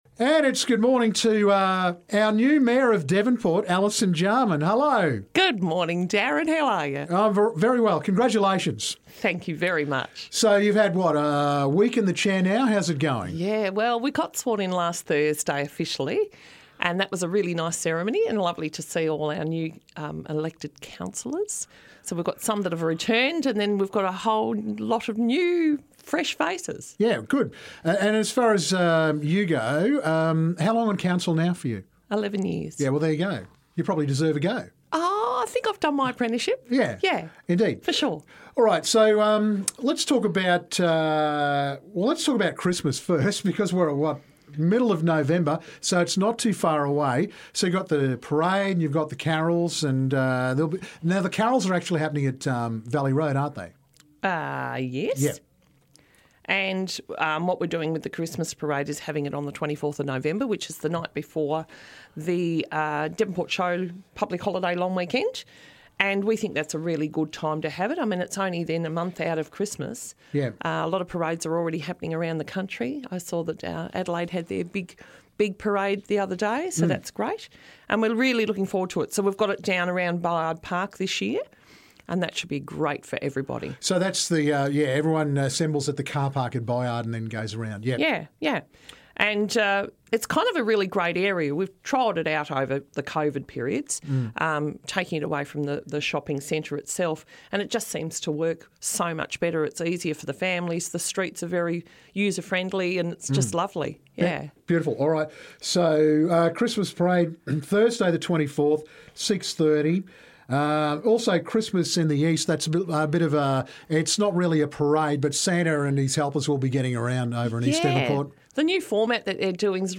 New Devonport Mayor Alison Jarman dropped in for a chat, including news on the William St works.